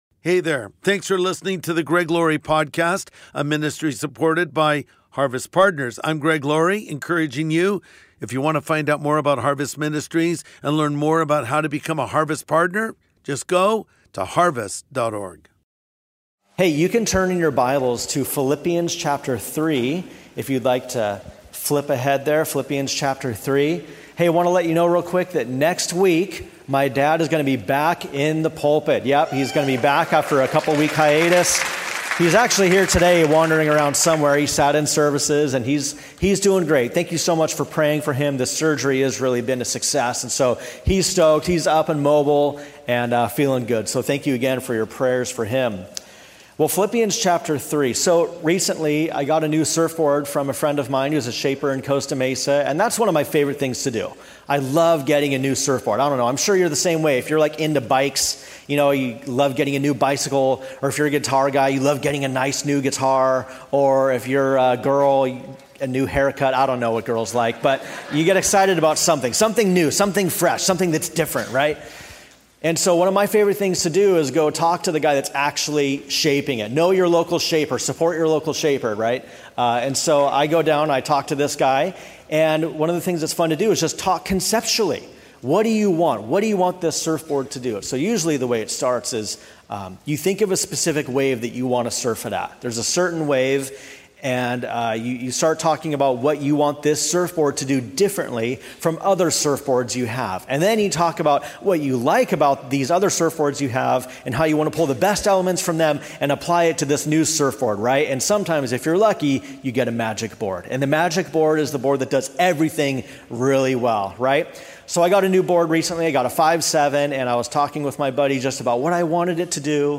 Going Forward | Sunday Message